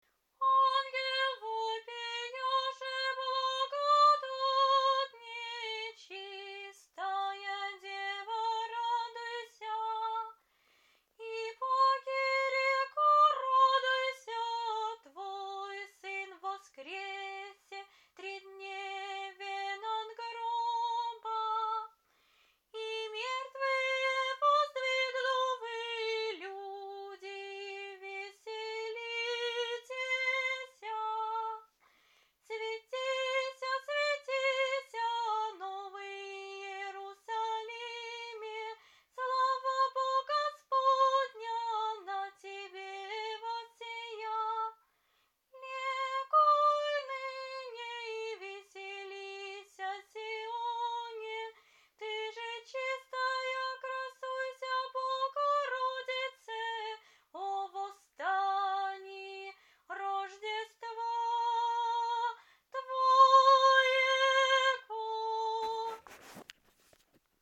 Задостойник Цветная Триодь